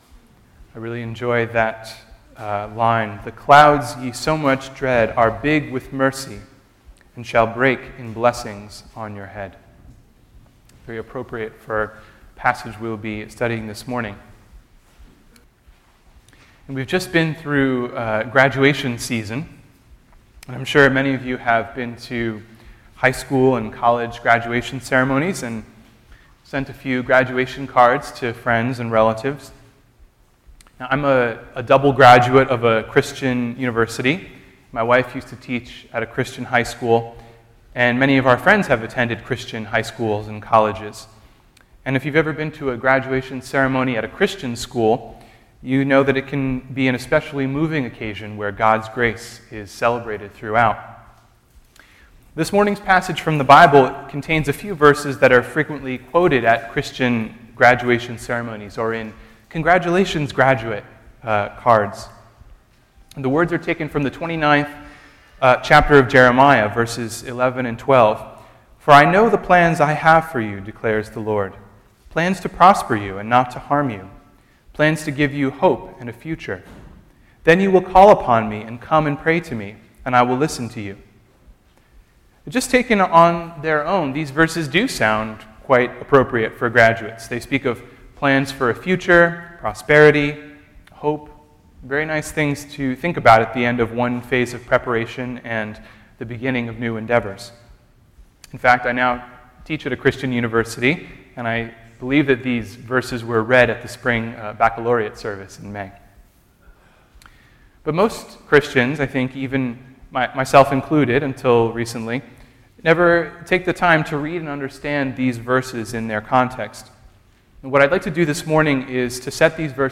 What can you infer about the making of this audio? This is the audio (32:05, 29.3MB) of a sermon delivered at Preakness Valley United Reformed Church on June 28 entitled, “A Future and a Hope?” The text is Jeremiah 29:1-14.